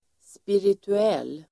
Ladda ner uttalet
Uttal: [spiritu'el:]